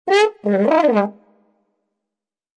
Descarga de Sonidos mp3 Gratis: trompeta comedia 1.